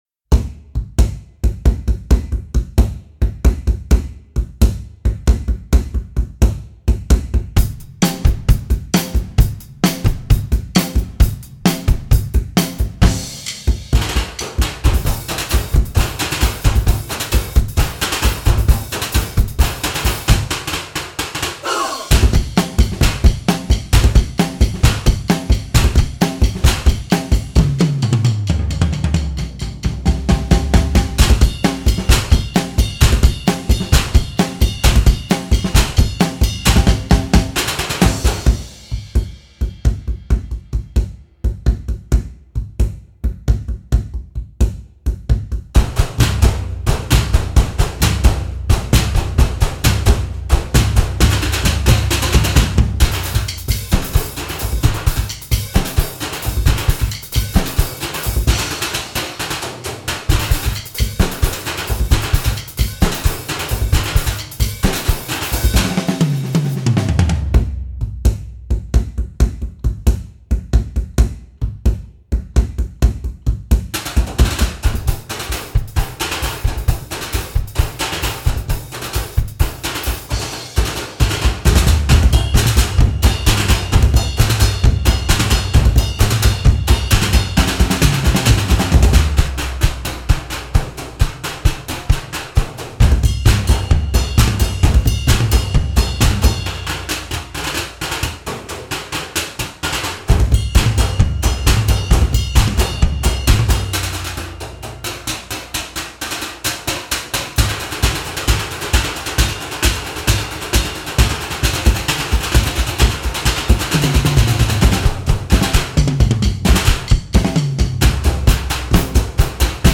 Voicing: Percussion Ensemble